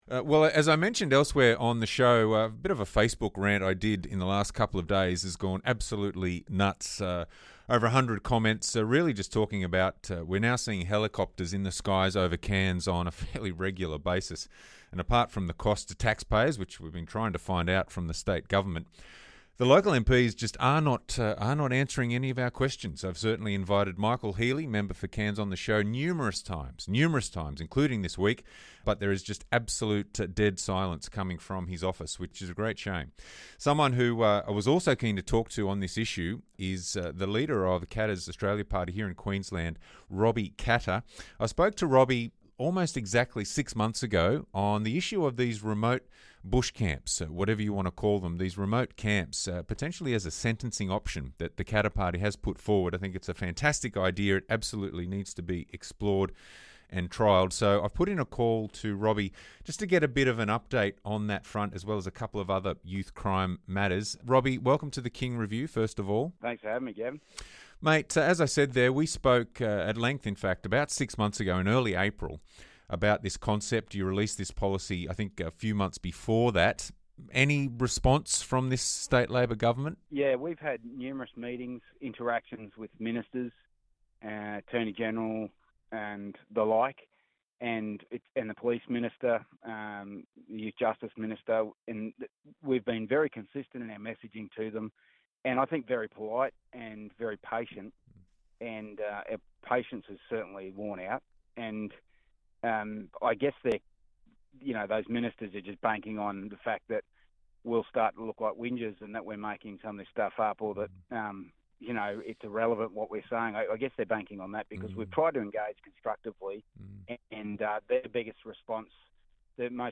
The King Review - Gavin speaks with KAP's State Leader Robbie Katter about remote bush camps for youth offenders and the “shameful” silence of local Labor MPs - 30 September 2022